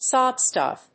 アクセントsób stùff